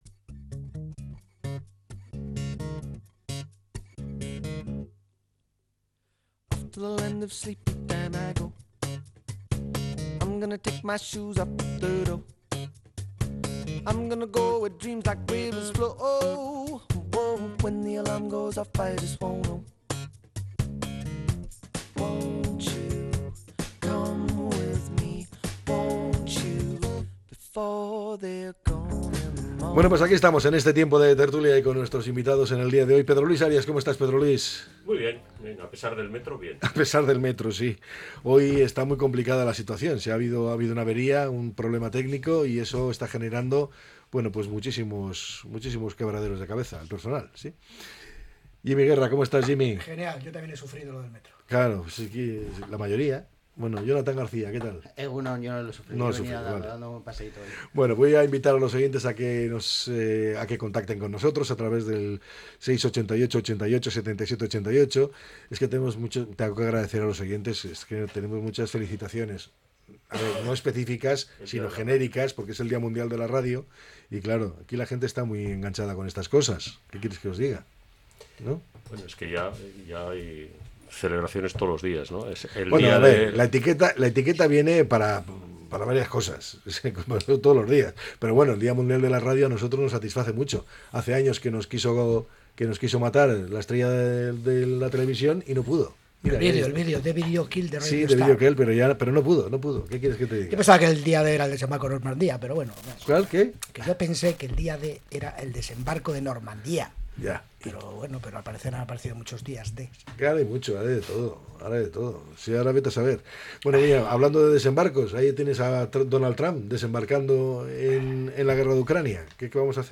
La tertulia 13-02-25.